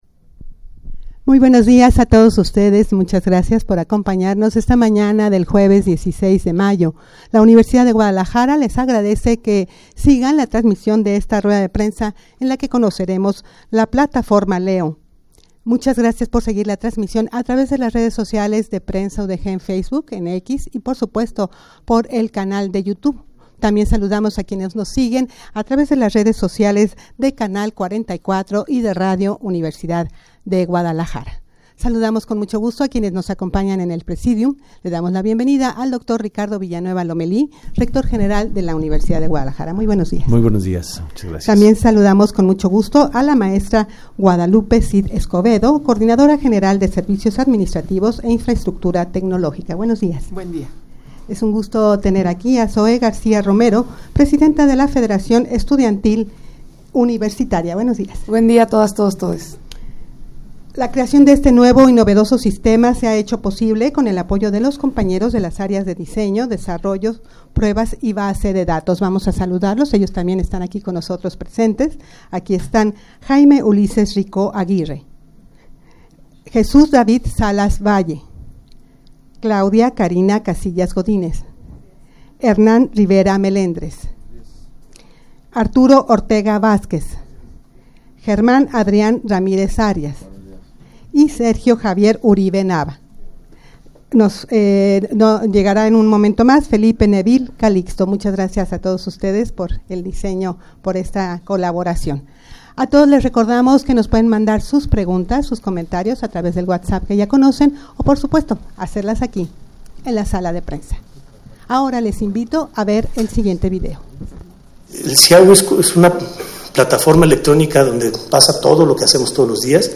Audio de la Rueda de Prensa
rueda-de-prensa-para-presentar-la-plataforma-leo-el-nuevo-siiau-escolar.mp3